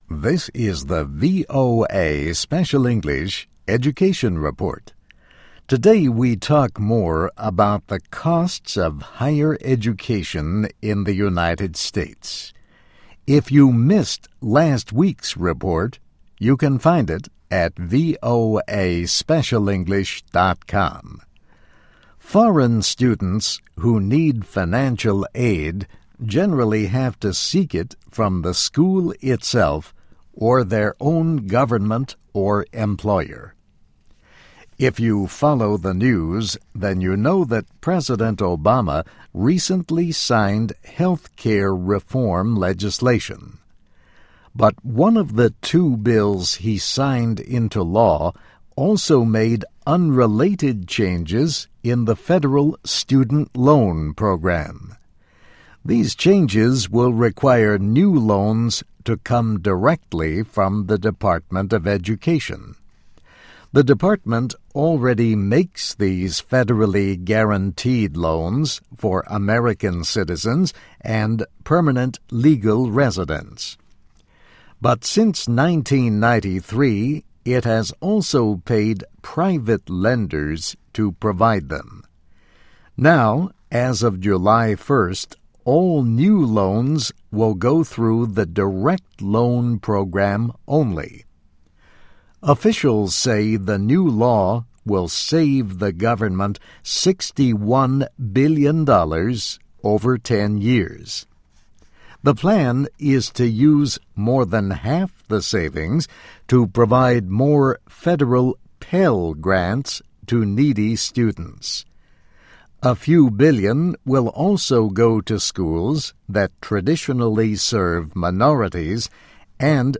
VOA Special English - Text & MP3